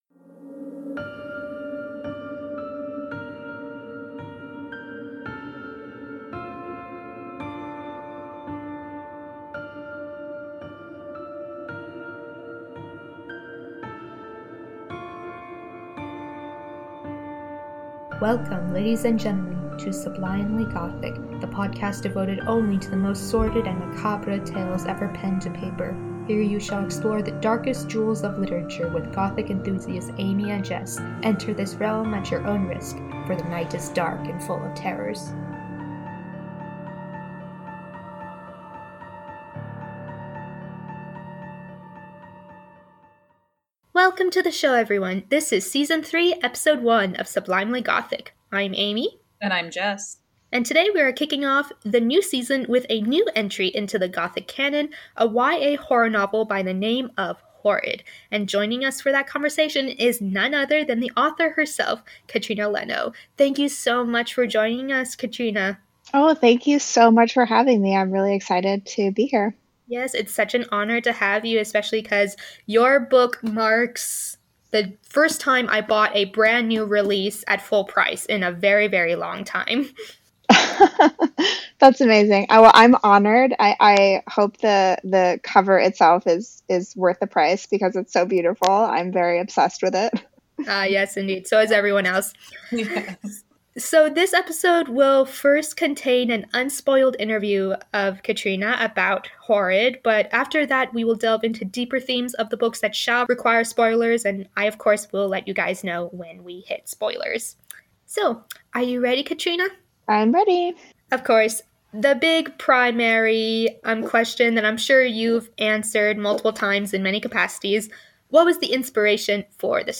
This episode contains an unspoiled interview